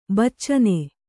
♪ baccane